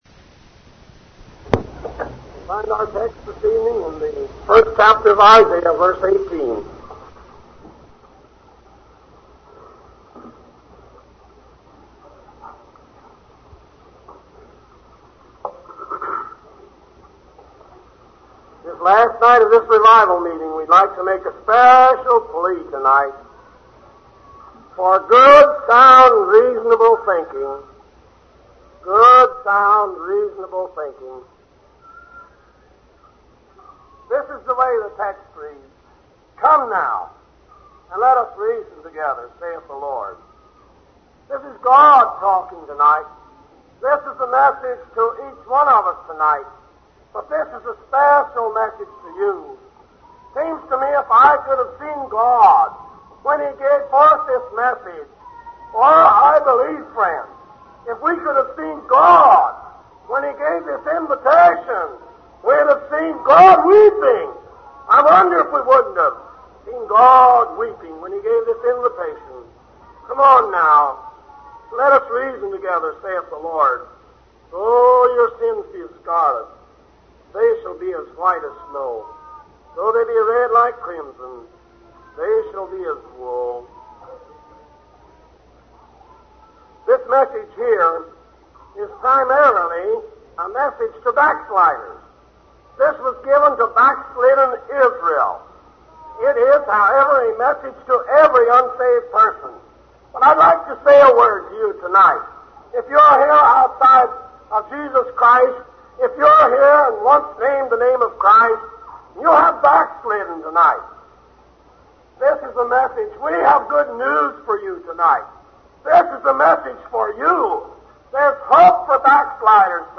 Sermons
Ridge View | Tent Meetings 2023